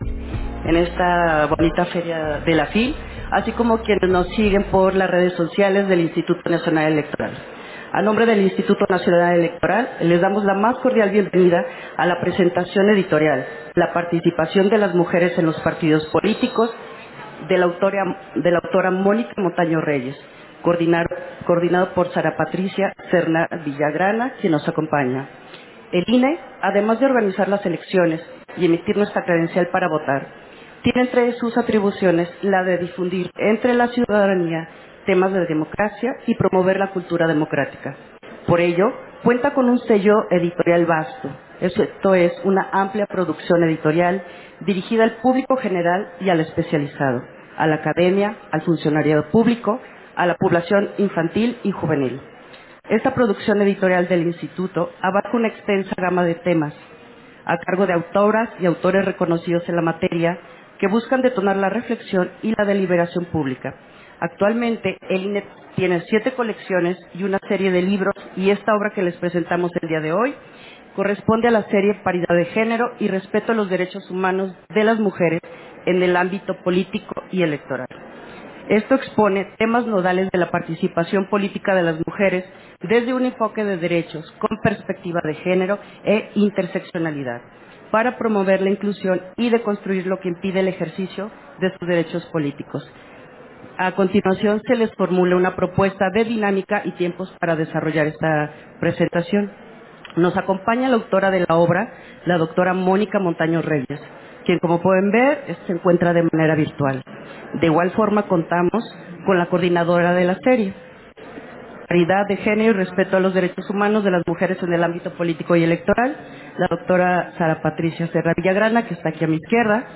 Versión estenográfica de la presentación editorial: Serie Piedad de Género, la participación de las mujeres en los partidos políticos, Fil Guadalajara 2024